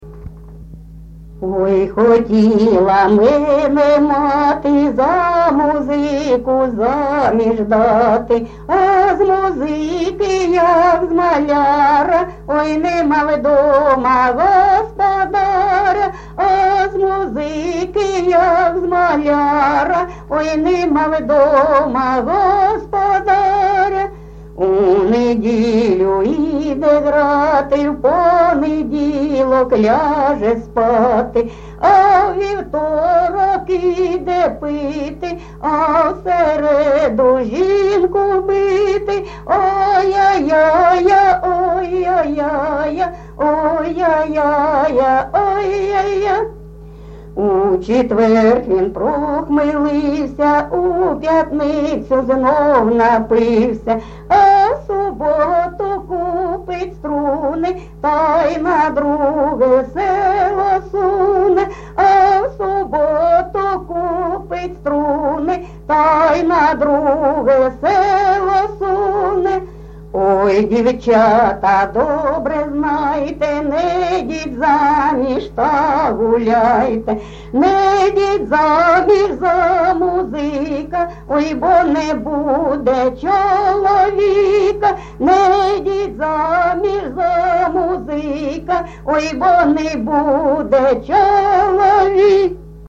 Місце записум. Часів Яр, Артемівський (Бахмутський) район, Донецька обл., Україна, Слобожанщина